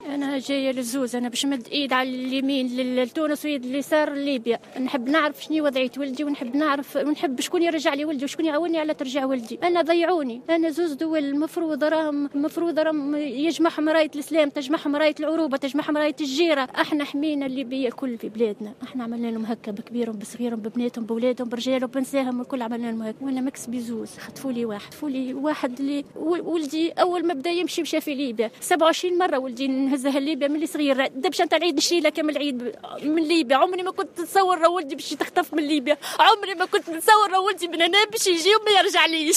على هامش مسيرة تضامنية مع الصحفيين المختطفين في ليبيا